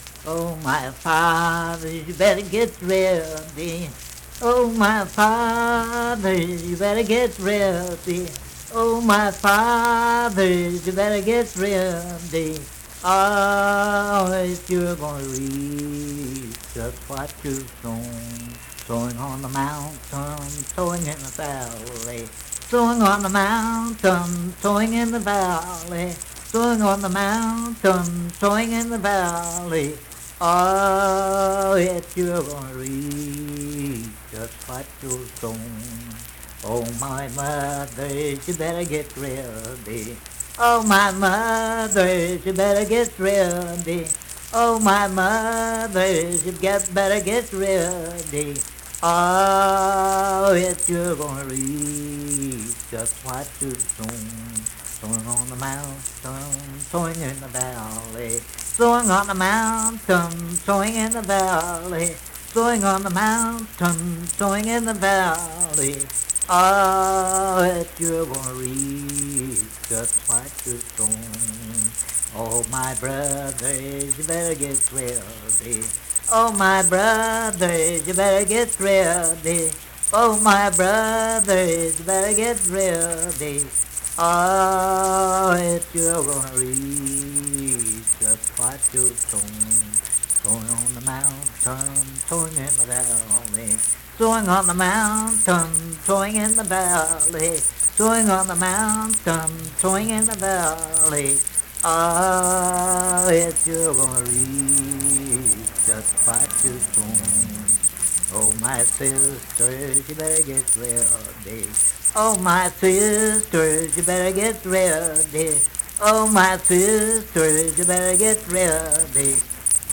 Unaccompanied vocal music
Verse-refrain 2(4)&R(4).
Hymns and Spiritual Music
Voice (sung)